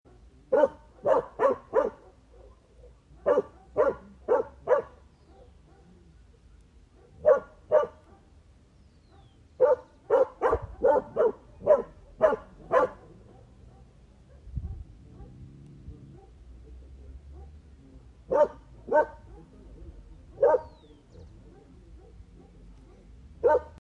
Dog Sound Button - Free Download & Play
Dog Sounds1,111 views